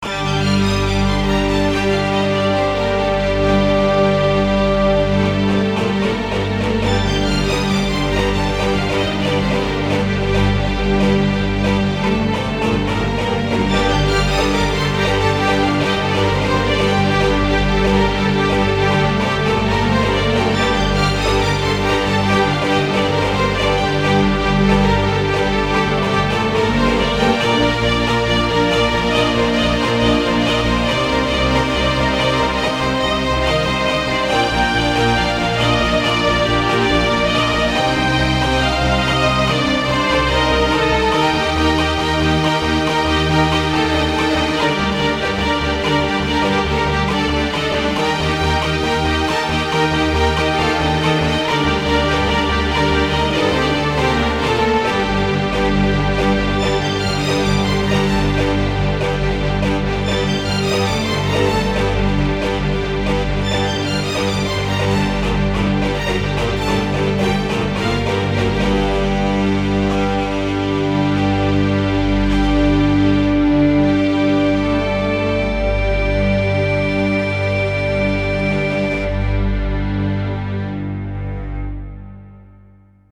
Mind stílusban, mind hangulatilag igazodnak az eredetiekhez.